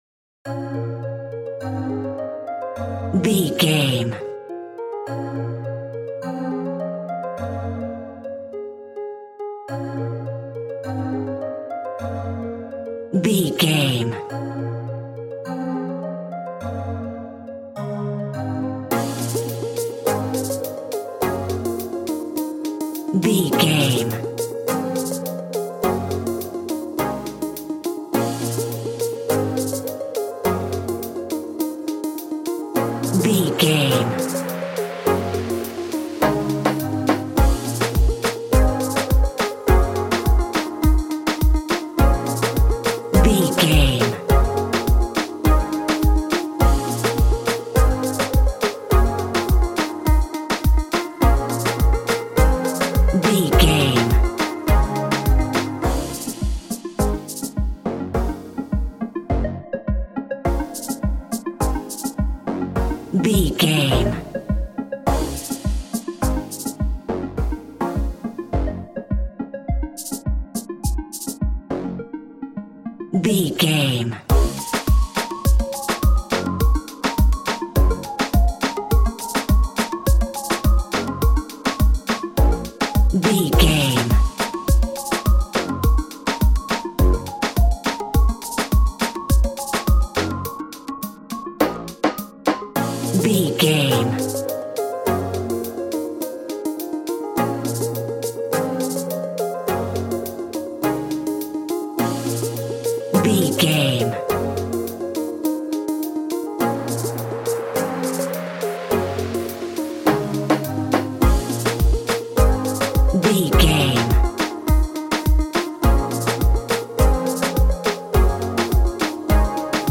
Classic reggae music with that skank bounce reggae feeling.
Aeolian/Minor
B♭
dub
laid back
chilled
off beat
drums
skank guitar
hammond organ
percussion
horns